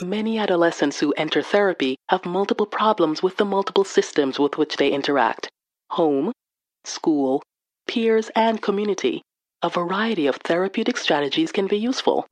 A vibrant, classy, intelligent North American voice talent, specializing in commercial, documentary and corporate narration.
My dual nationality background and international experience brings you a voice with standard American diction that is familiar and comfortable for your international audience.
Sprechprobe: eLearning (Muttersprache):